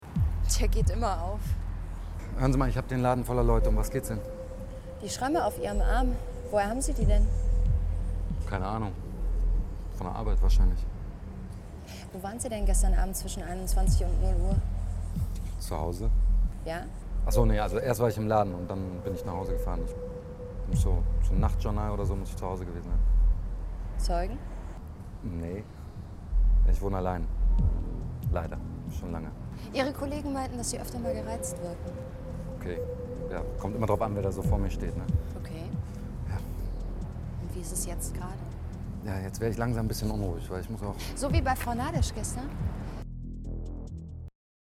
dunkel, sonor, souverän
Mittel minus (25-45)
Audio Drama (Hörspiel)